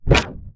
ZAP_Subtle_05_mono.wav